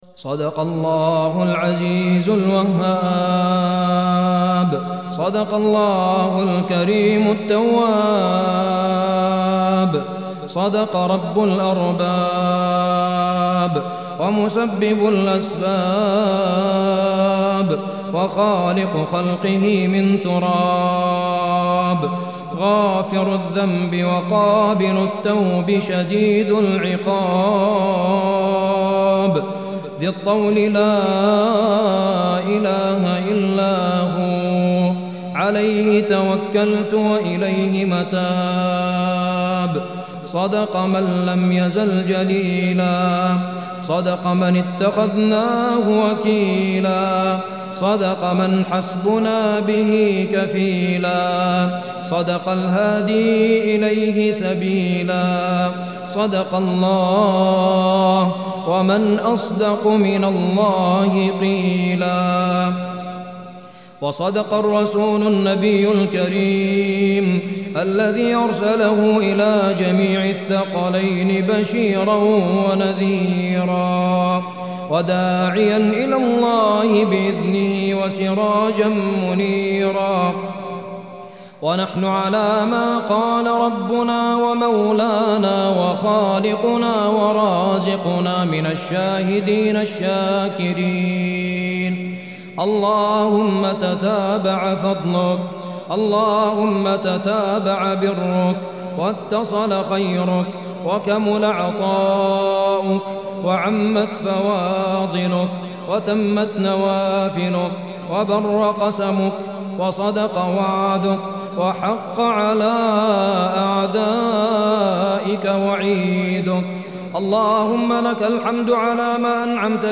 دعاء